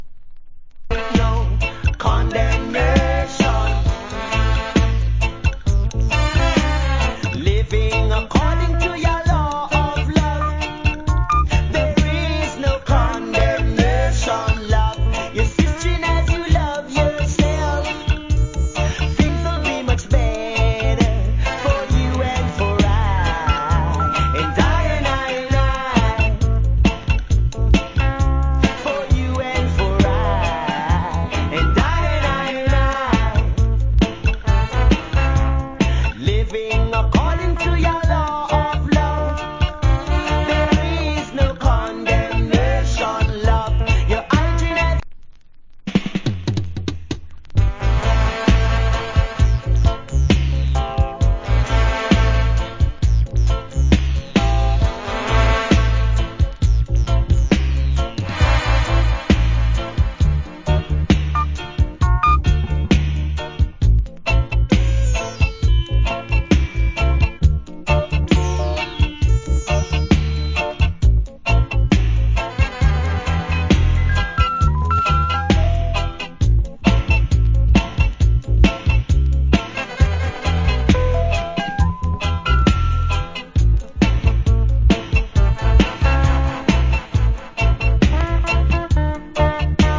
Early 80's. Wicked Roots Vocal.